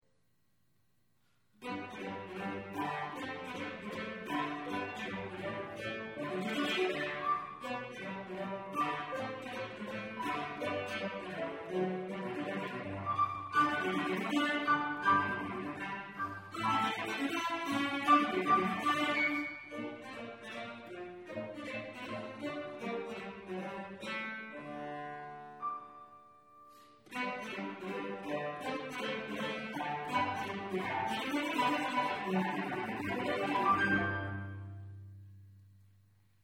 Danza de brujas. Cello